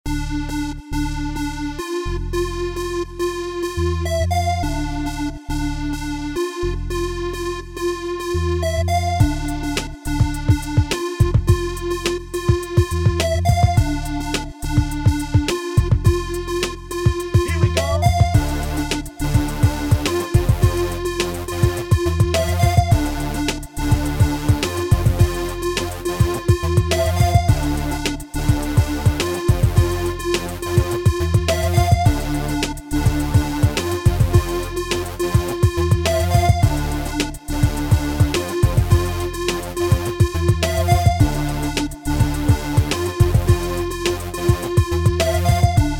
Минуса рэп исполнителей